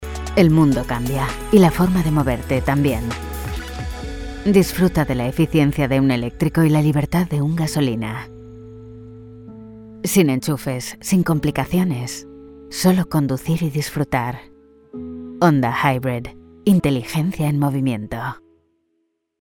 Automotive
Castilian Spanish online voice over artist fluent in English.
Soundproof recording booth ( studiobricks)
Mic Neumann U87 Ai